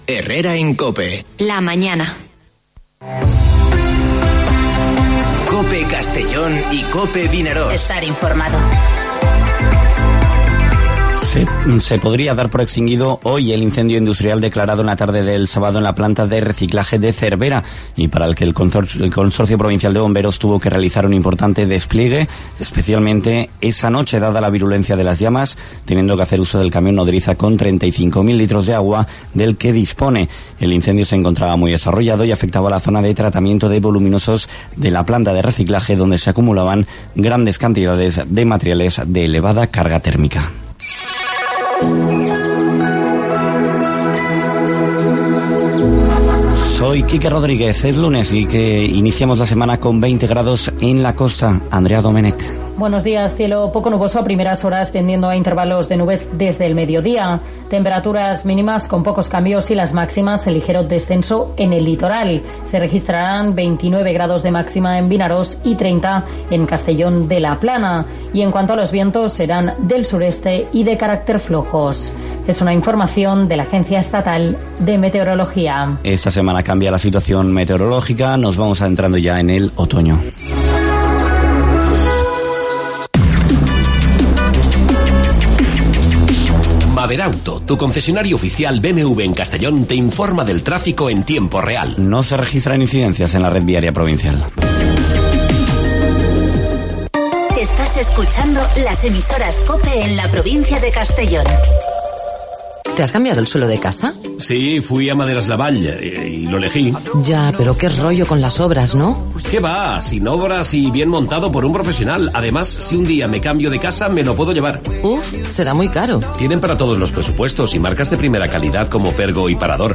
Informativo Herrera en COPE en la provincia de Castellón (14/09/2020)